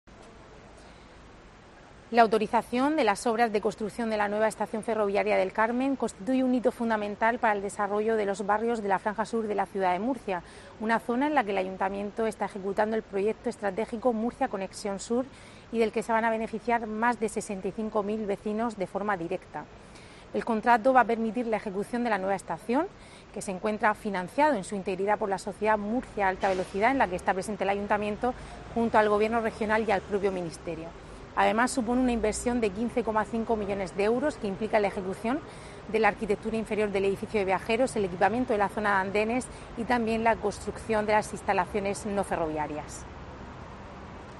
Rebeca Pérez, vicealcaldesa de Murcia